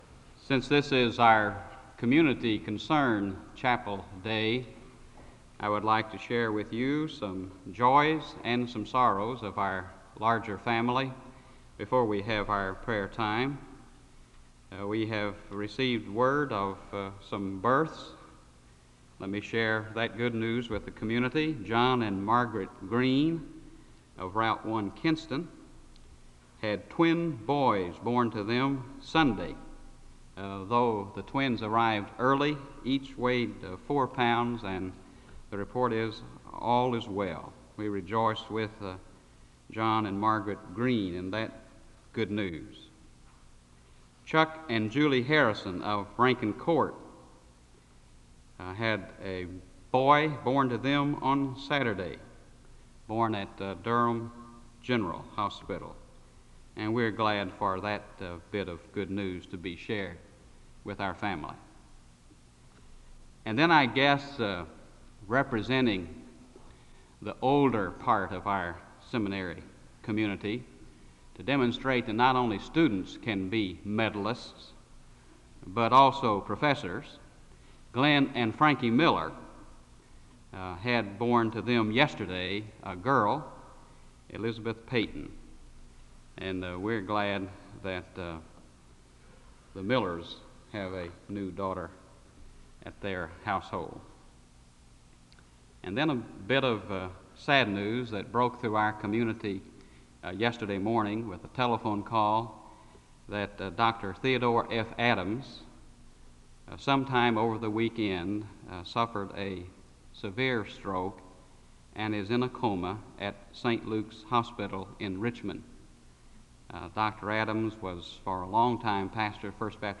V.A. The service begins with an announcement of community concerns.(00:00-02:27). The announcement is followed by a period of both silent and public prayer (02:28-05:56).
The choir leads in a song of worship (08:28- 11:52).
The service finishes with a closing song (27:38-28:30).